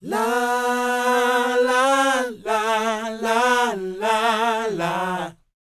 NA-NA E4A -R.wav